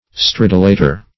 stridulator - definition of stridulator - synonyms, pronunciation, spelling from Free Dictionary
\Strid"u*la`tor\